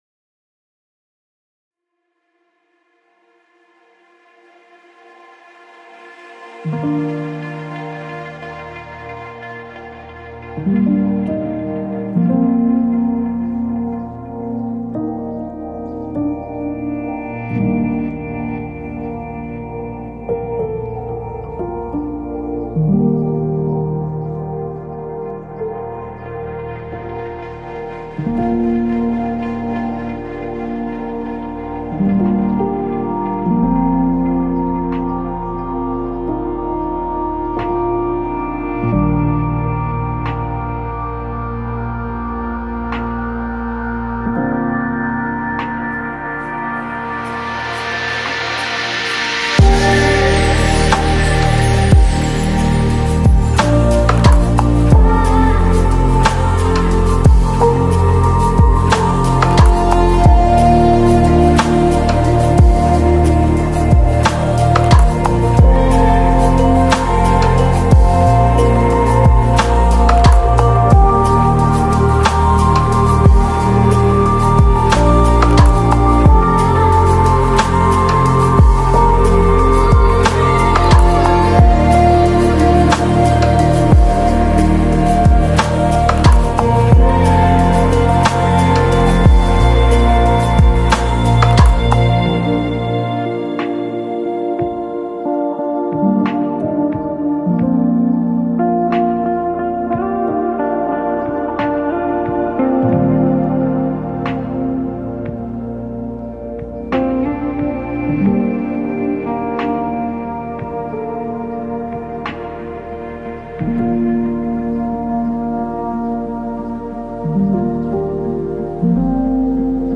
это завораживающая композиция в жанре неофолк